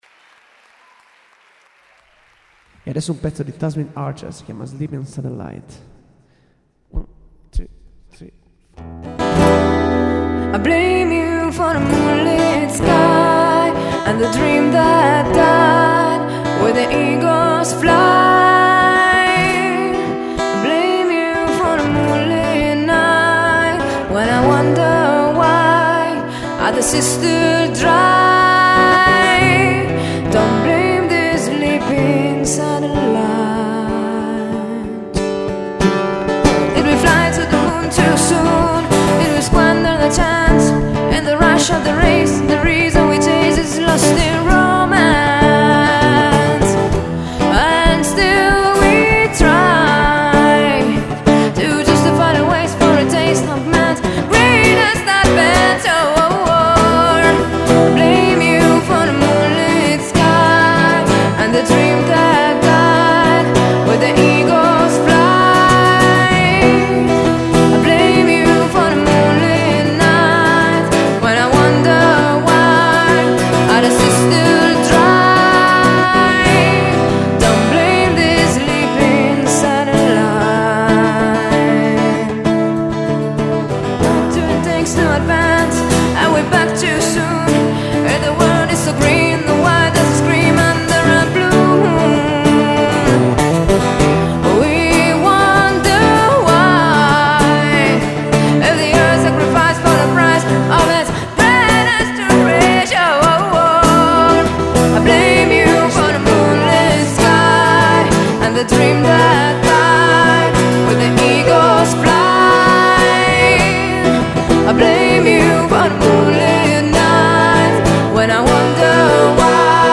cover di vario genere riarrangiate in chiave acustica
live @ Kanakacafè, RE